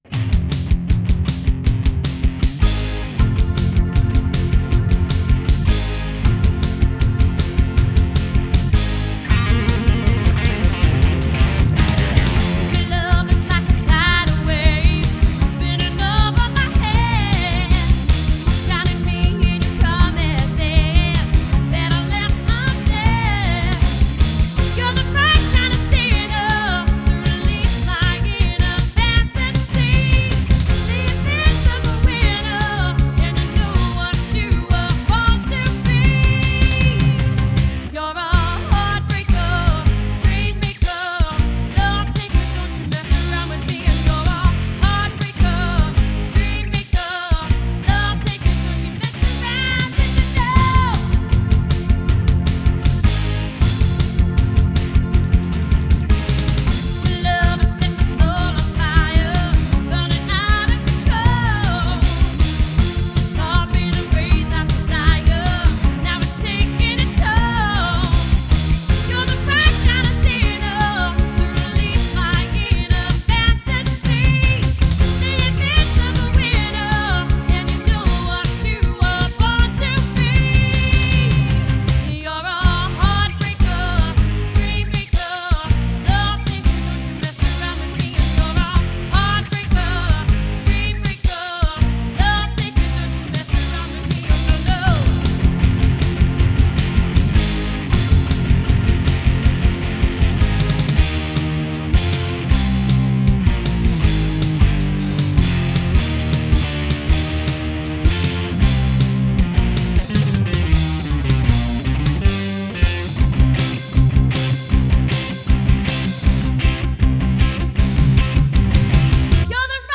Feminine Edged Rock Band